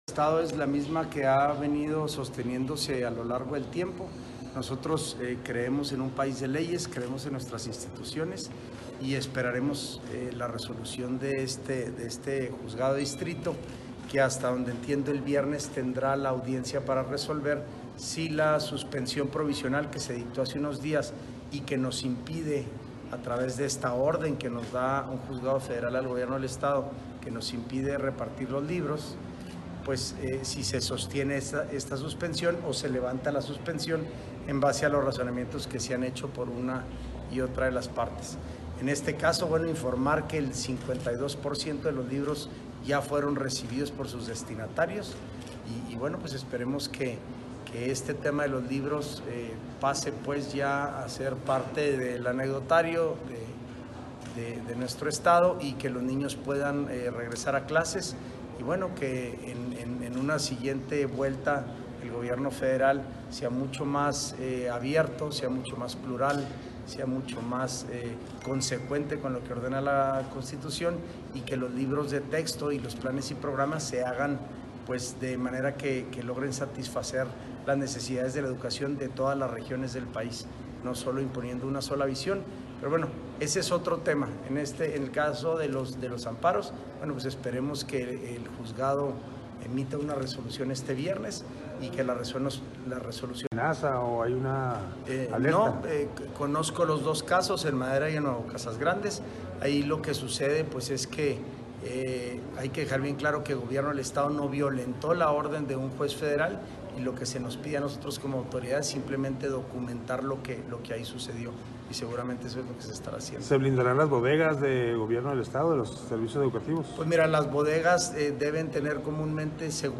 AUDIO: SANTIAGO DE LA PEÑA, SECRETARÍA GENERAL DE GOBIERNO (SGG)